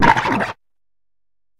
Cri de Chochodile dans Pokémon HOME.